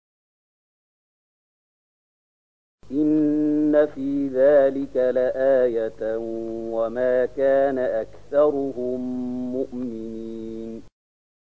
026008 Surat Asy-Syu`araa’ ayat 8 dengan bacaan murattal ayat oleh Syaikh Mahmud Khalilil Hushariy: